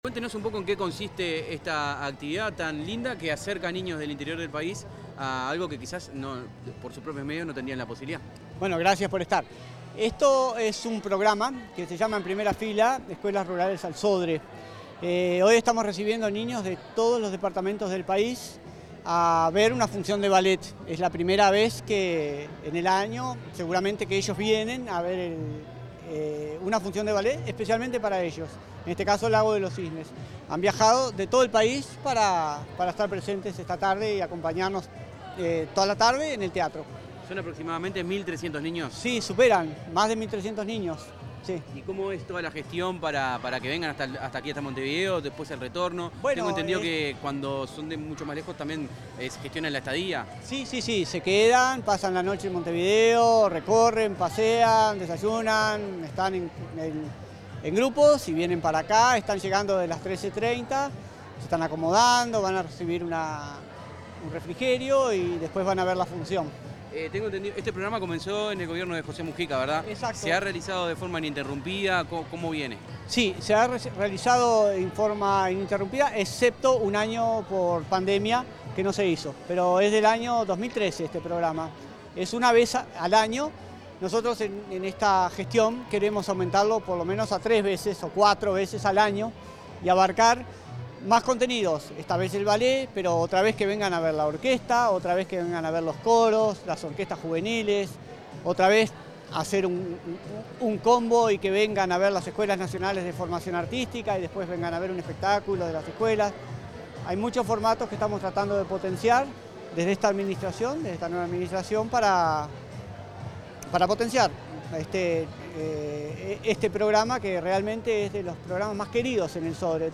Declaraciones del presidente del Sodre, Luis Pérez Aquino
Declaraciones del presidente del Sodre, Luis Pérez Aquino 21/05/2025 Compartir Facebook X Copiar enlace WhatsApp LinkedIn El presidente del Sodre, Luis Pérez Aquino, dialogó con la prensa luego de la actividad En Primera Fila: Escuelas Rurales al Auditorio, del Ministerio de Educación y Cultura y la Administración Nacional de Educación Pública, realizada en el Auditorio Nacional del Sodre.